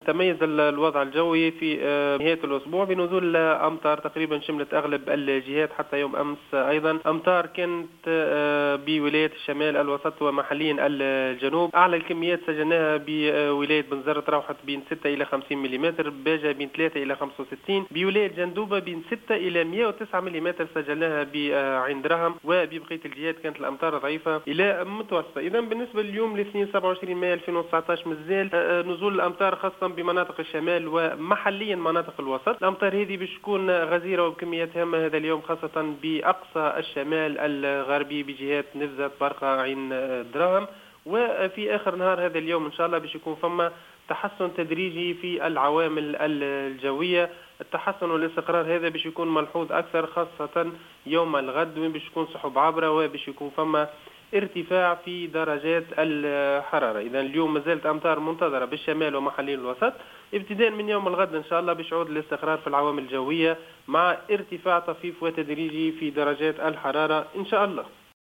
في تصريح للجوهرة اف ام